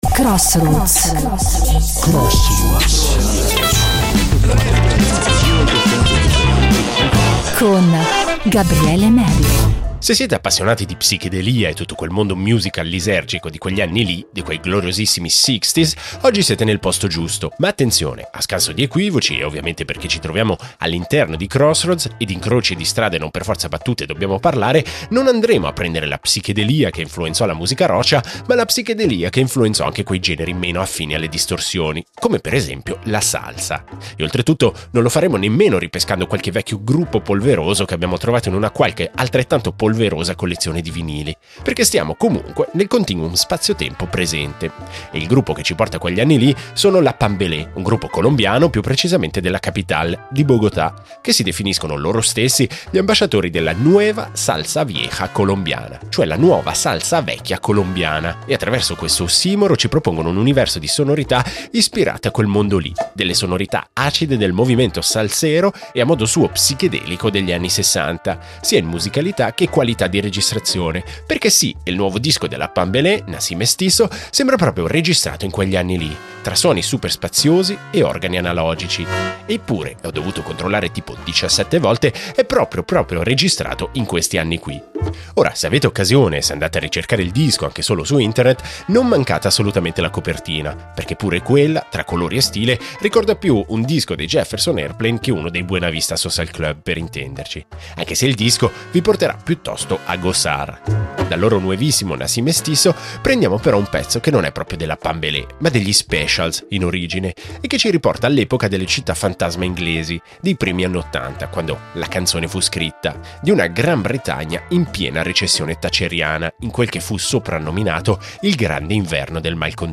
riportano in vita la psichedelia della salsa anni ‘60
offre un sound analogico e spazioso